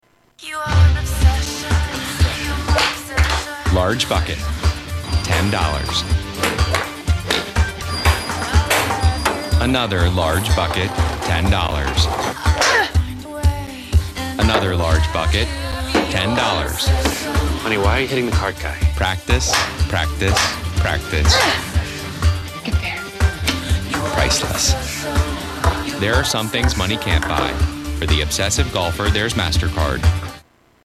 Tags: Media MasterCard Advertisement Commercial MasterCard Clips